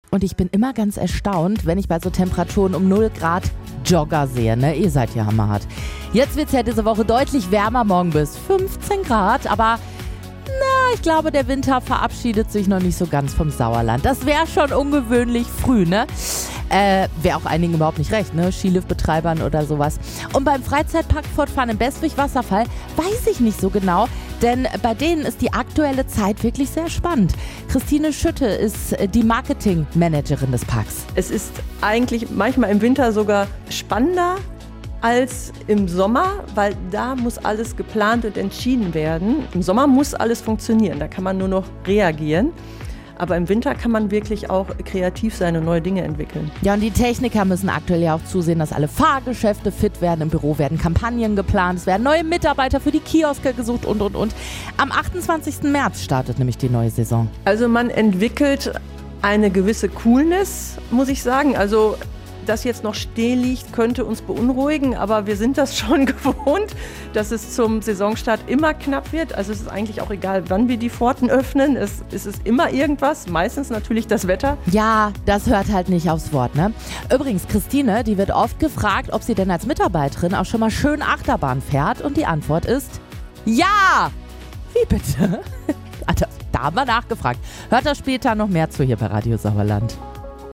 Wir waren vor Ort und haben uns den Freizeitpark im Winterschlaf angeschaut.